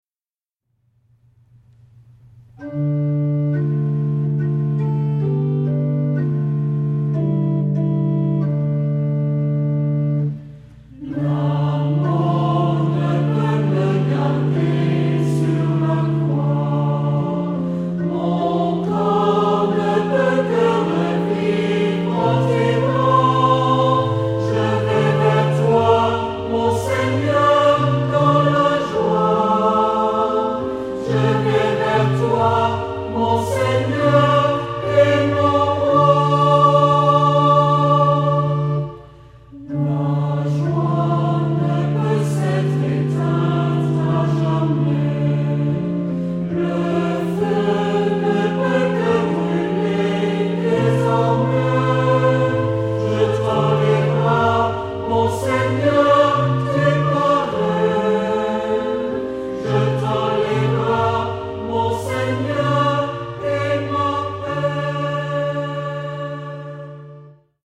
Genre-Style-Form: Canticle
Mood of the piece: lively ; ample
Type of Choir:  (1 unison voices )
Instrumentation: Organ  (1 instrumental part(s))
Tonality: D minor